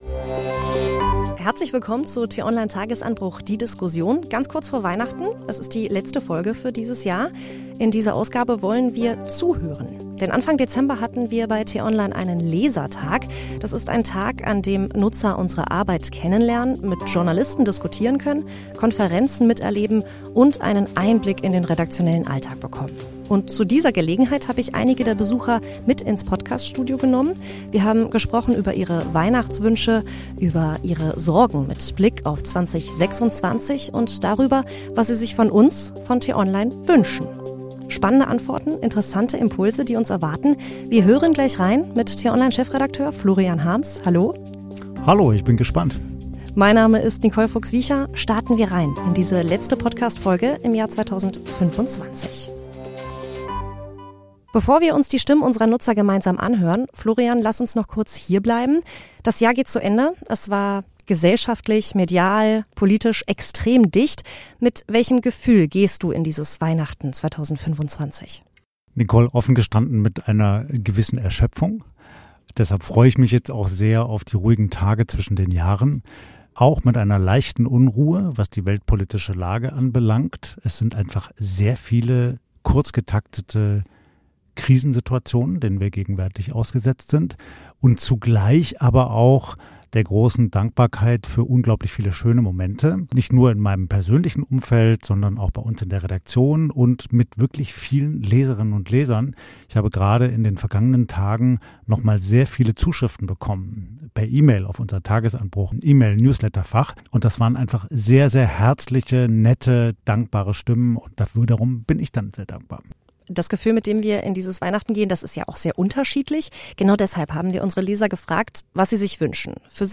Tagesanbruch – die Diskussion Kurz vor Weihnachten wird’s persönlich: Beim t-online-Lesertag haben wir Besucher ins Podcaststudio geholt.
Eine Jahresendfolge zum Zuhören: ehrlich, nachdenklich, manchmal hoffnungsvoll.